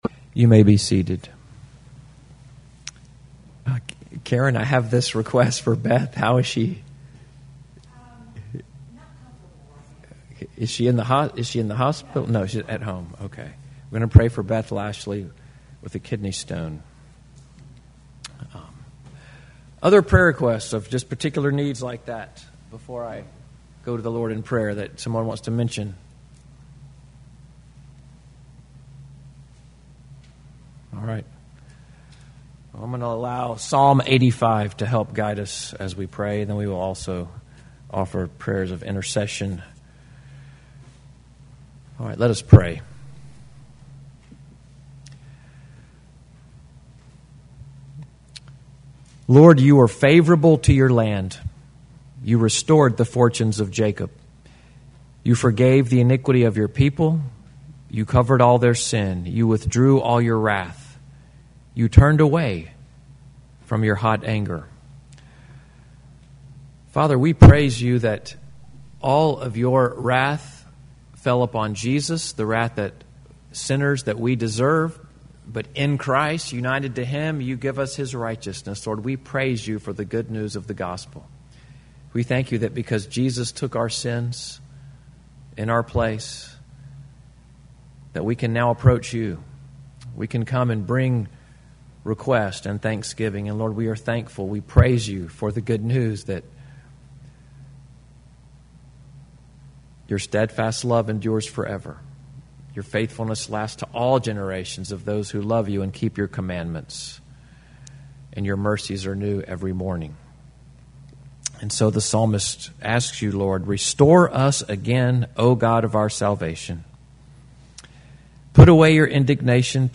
8/4/2024 Pastorial Prayer